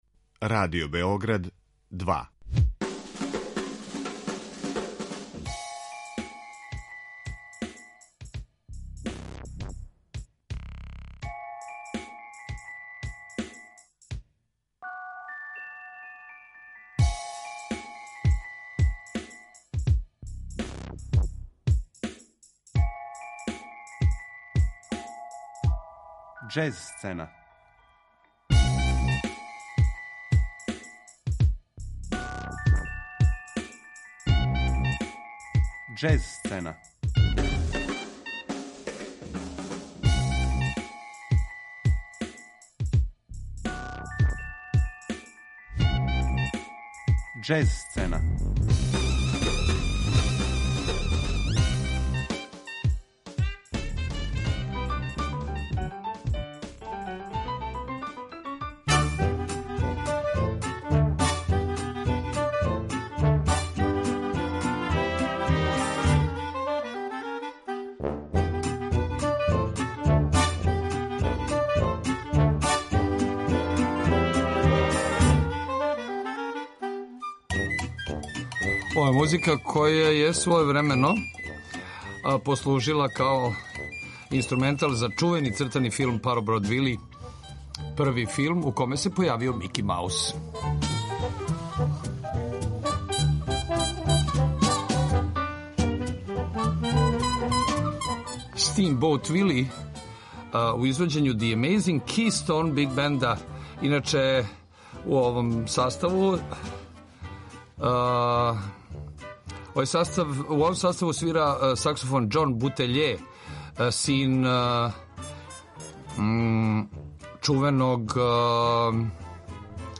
алт саксофониста
и ову колекцију чини ауторски програм у стилу необапа